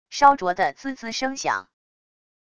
烧灼的滋滋声响wav音频